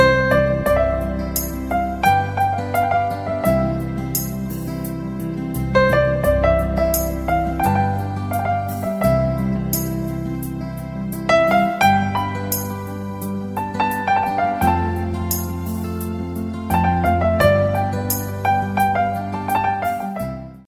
Instrumental Ringtones